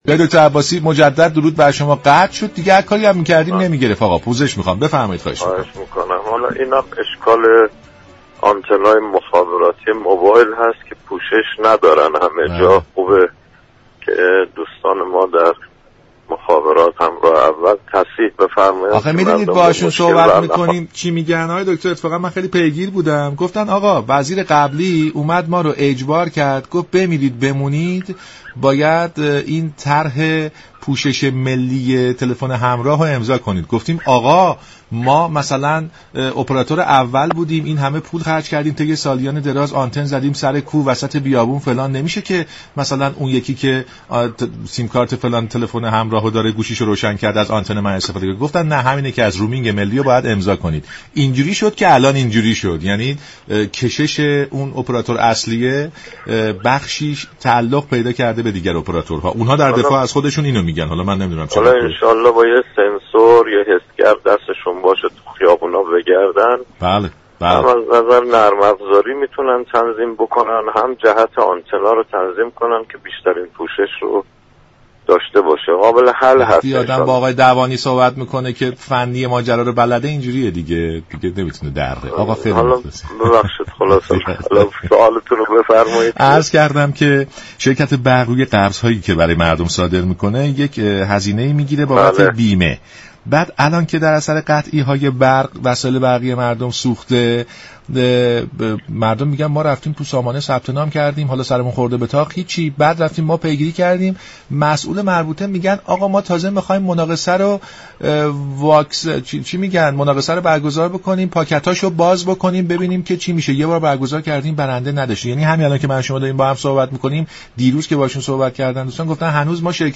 به گزارش شبكه رادیویی ایران، فریدون عباسی دوانی عضو كمیسیون انرژی مجلس در برنامه «سلام صبح بخیر» رادیو ایران در این باره گفت: تا سال گذشته وزارت نیرو هزینه ای كه از مردم برای بیمه می گرفت، مستقیم به خزانه واریز می كرد اشكال كار آنجا بود كه خزانه پول را به وزارت نیرو باز نمی گرداند.